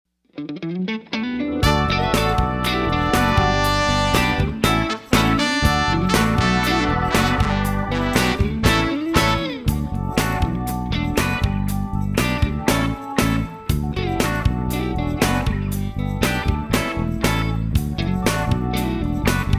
Listen to a sample of this instrumental song